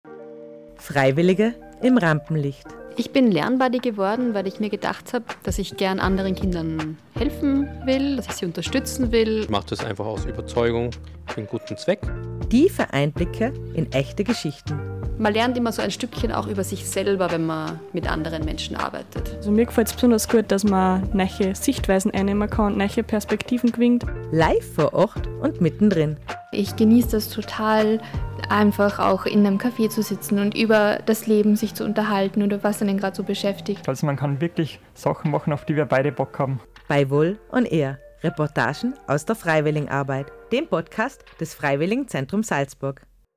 Trailer #6 ~ VOL ON AIR –- Reportagen aus der Freiwilligenarbeit Podcast
direkt vor Ort, mit viel Interaktion und spannenden Gesprächen.